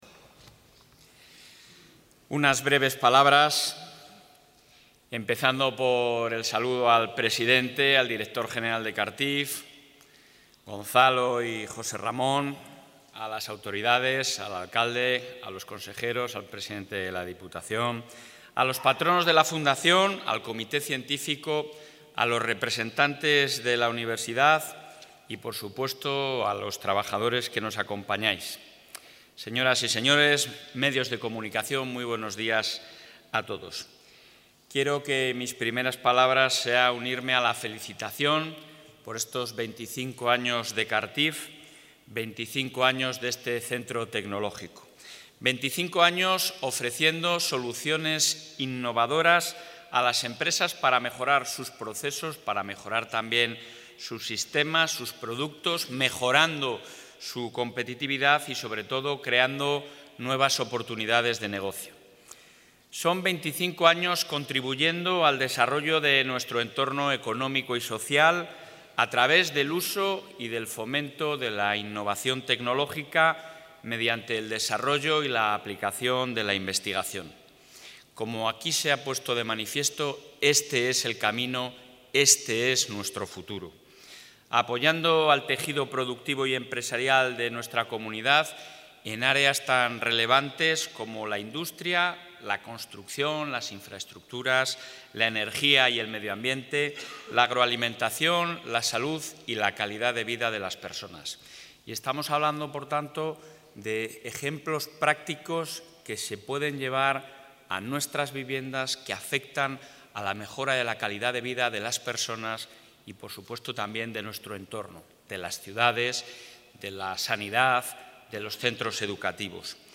Durante la celebración de los 25 años del Centro de Automatización, Robótica y Tecnologías de la...
Intervención del presidente.